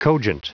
Prononciation du mot cogent en anglais (fichier audio)
Prononciation du mot : cogent